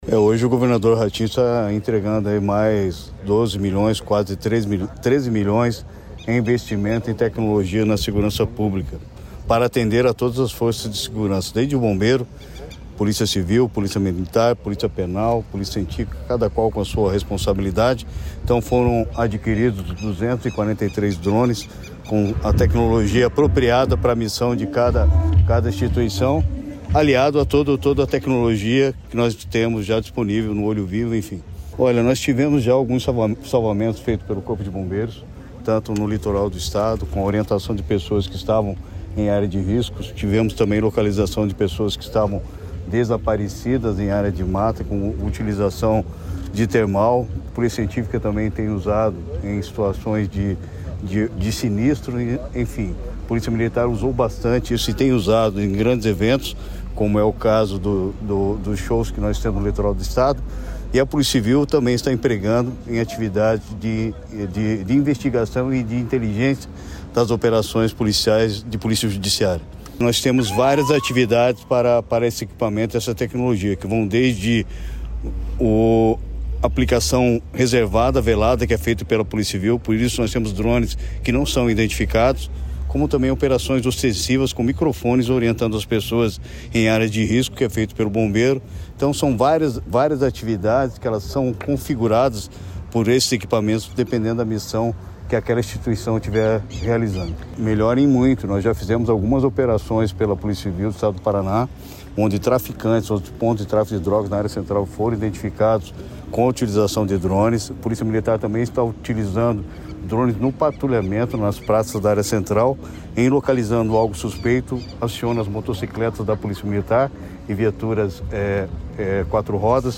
Sonora do secretário da Segurança Pública, Hudson Leôncio Teixeira, sobre a entrega de 243 drones para monitoramento aéreo das forças de segurança do Paraná